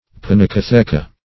Search Result for " pinacotheca" : The Collaborative International Dictionary of English v.0.48: Pinacotheca \Pin`a*co*the"ca\, n. [L. pinacotheca, fr. Gr.
pinacotheca.mp3